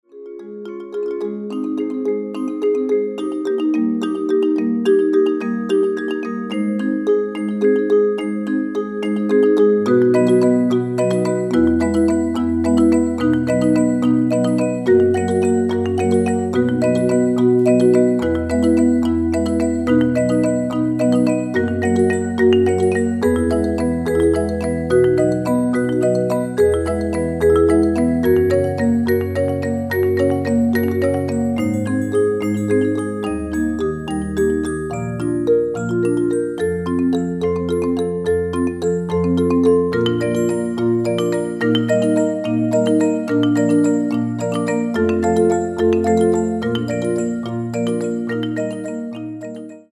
original motion picture score
a delicate, but stirring work